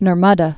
(nər-mŭdə) also Nar·ba·da (-bŭdə)